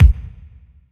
• Roomy Steel Kick Drum Sample D Key 283.wav
Royality free bass drum single hit tuned to the D note. Loudest frequency: 203Hz
roomy-steel-kick-drum-sample-d-key-283-KAE.wav